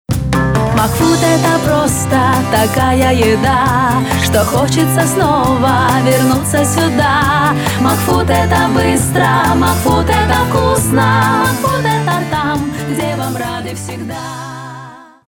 Радио-ролик МакФуд Категория: Аудио/видео монтаж